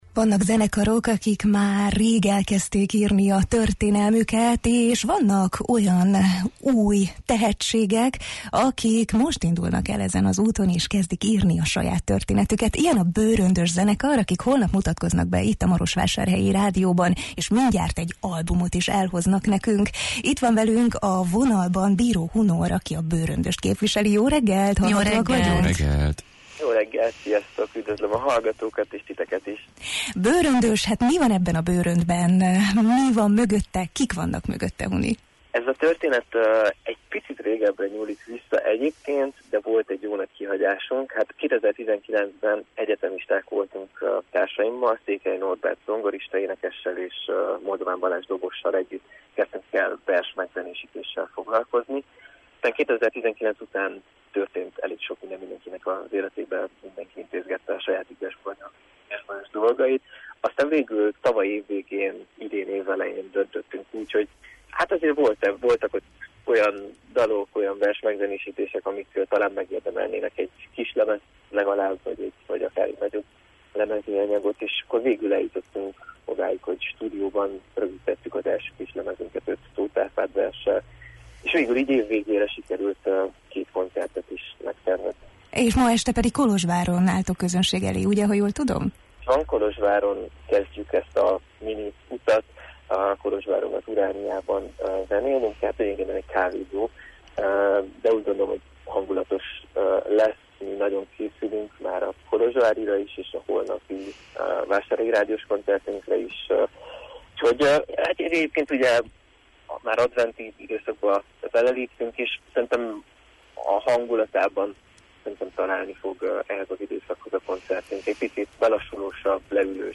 beszélgettünk